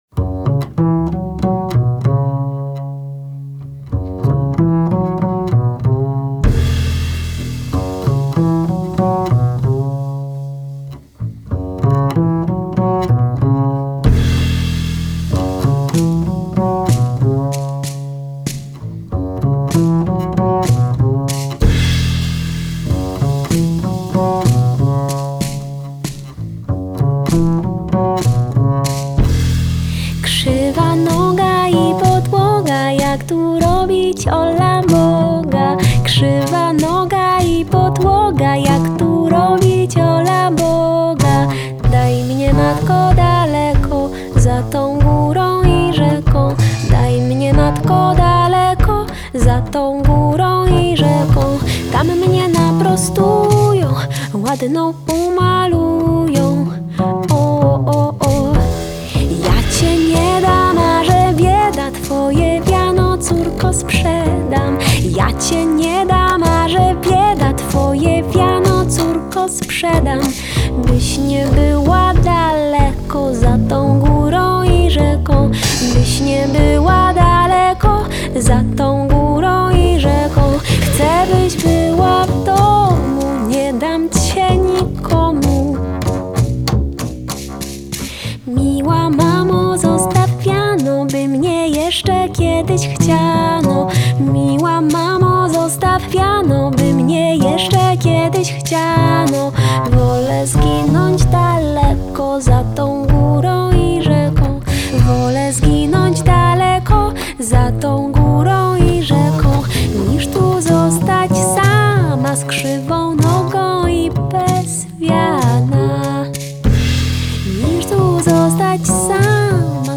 Genre: Vocal Jazz, Avant-Garde, Folk
klarnet, drumla, śpiew (clarinet, jaws harp, vocal)
kontrabas (double bass)